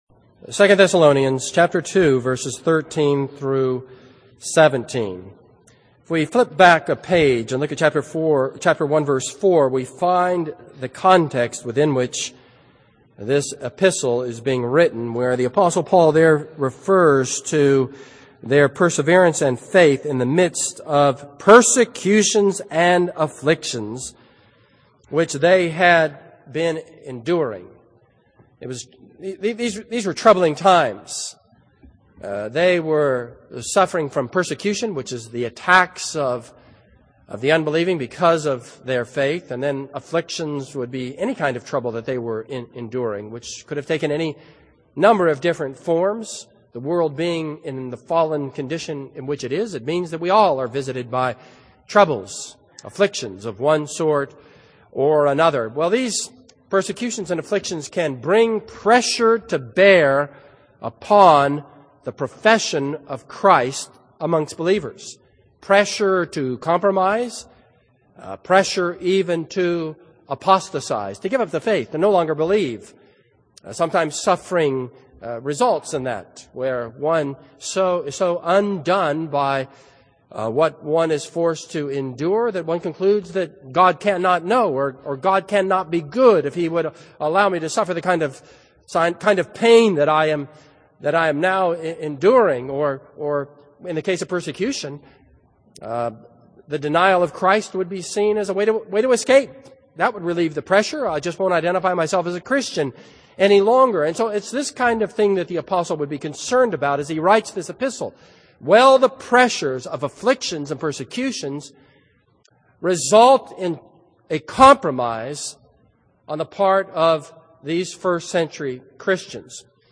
This is a sermon on 2 Thessalonians 2:13-17.